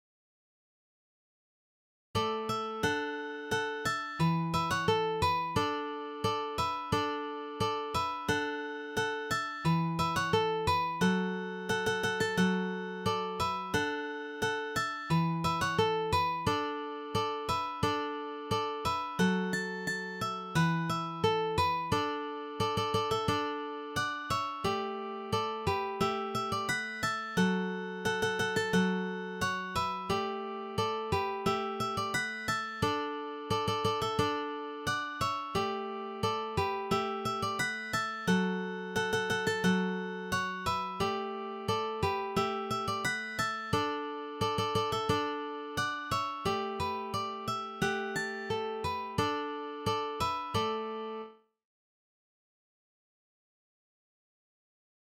three guitars